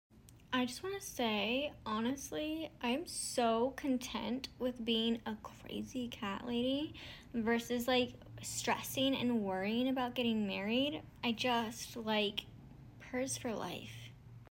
Meow Sound Effects Free Download
Meow sound effects free download